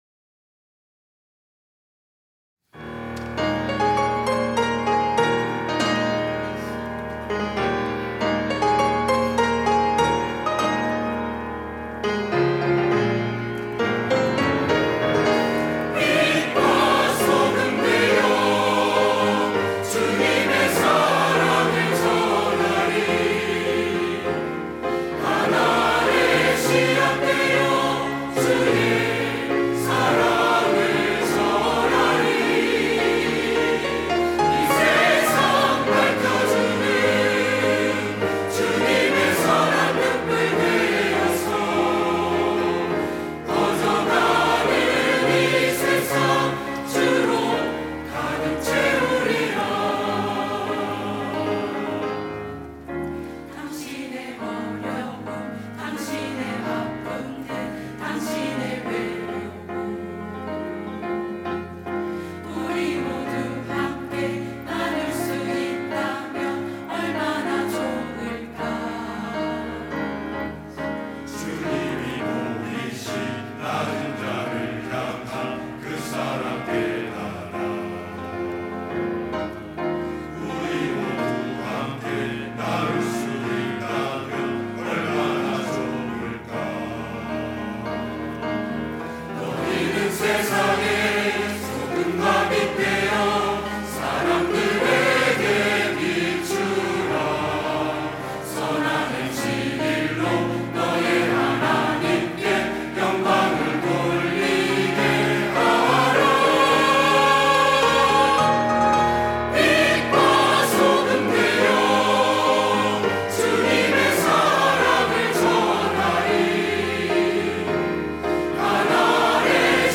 시온(주일1부) - 빛과 소금되어
찬양대